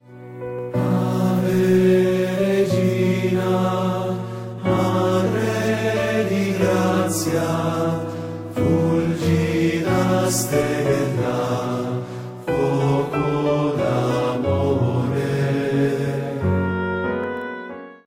Un canto mariano di grande intensit�.